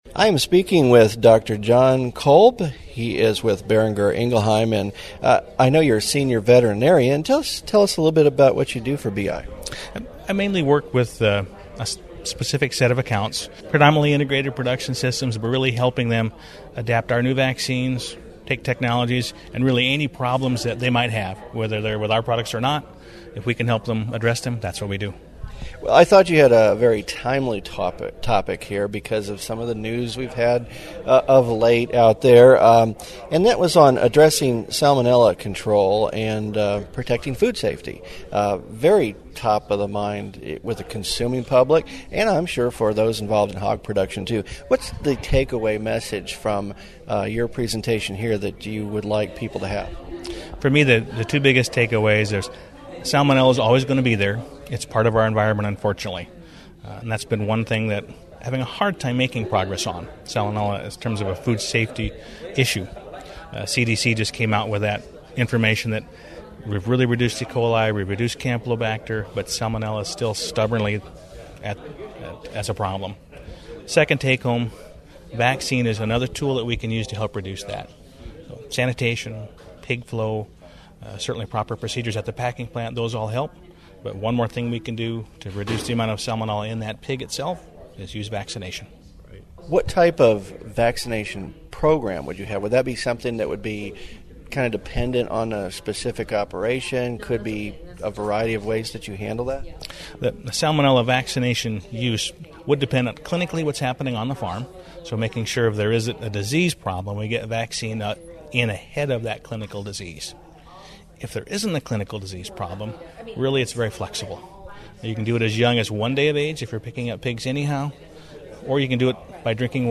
Boehringer Ingelheim Vetmedica (BIVI) hosted a media event on the last day of World Pork Expo that focused on Keeping Food Safe and Profits Growing.